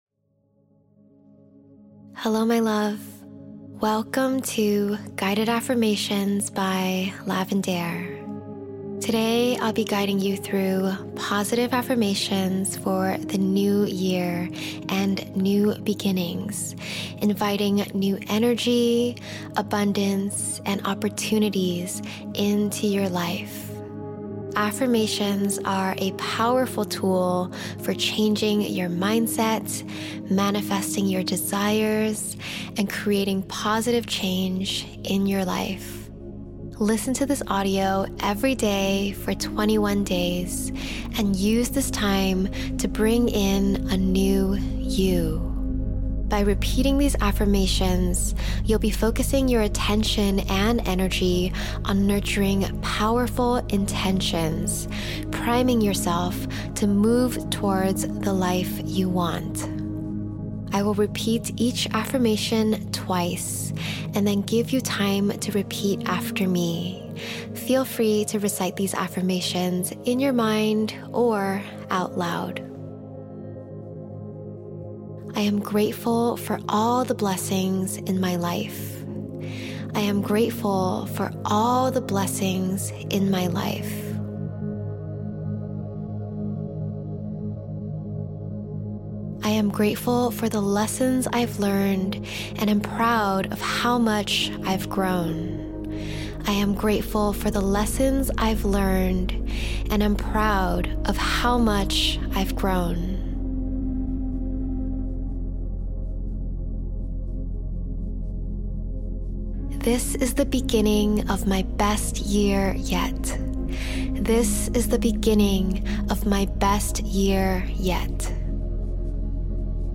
Positive Affirmations for Abundance, Opportunity, Alignment ✨